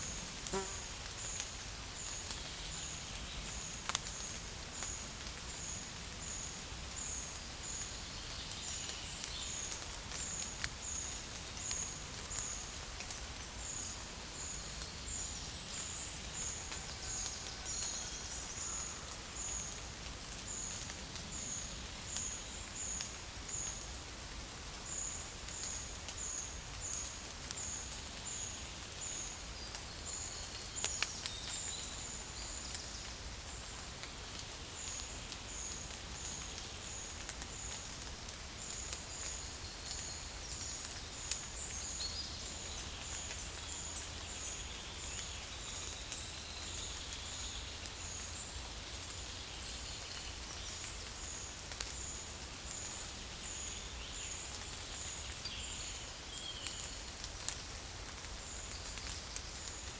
Monitor PAM
Certhia familiaris
Certhia brachydactyla
Turdus iliacus